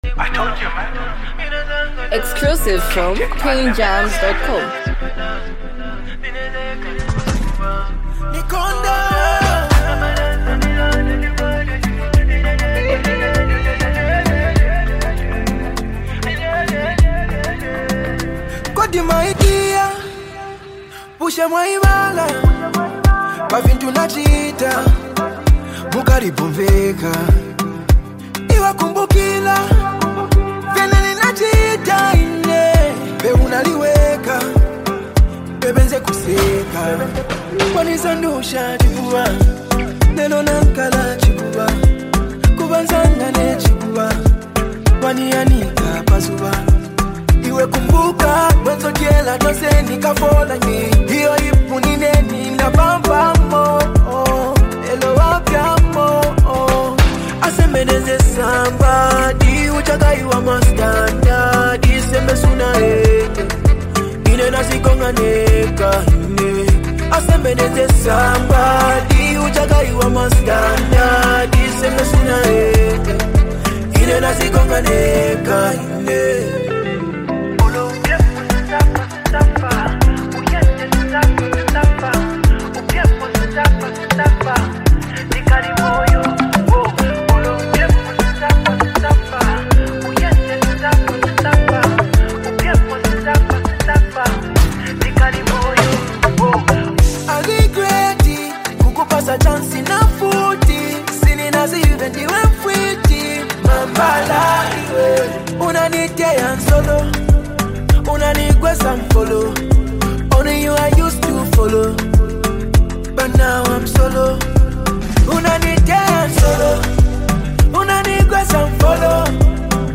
an emotional love song